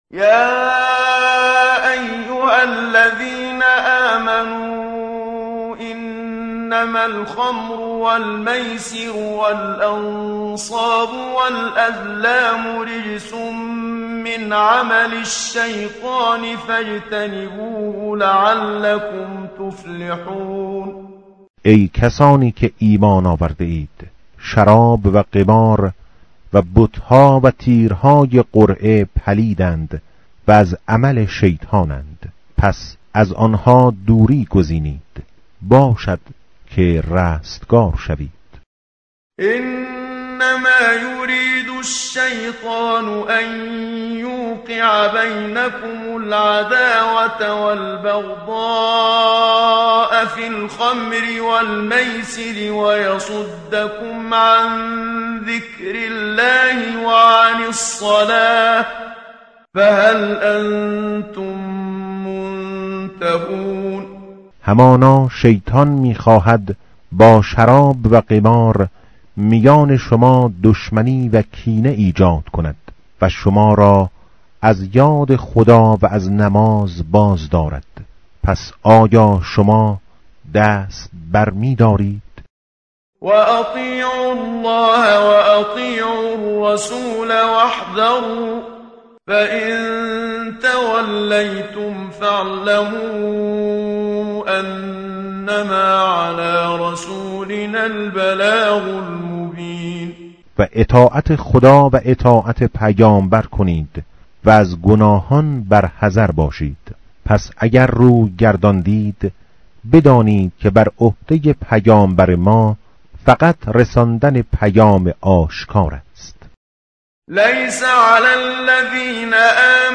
متن قرآن همراه باتلاوت قرآن و ترجمه
tartil_menshavi va tarjome_Page_123.mp3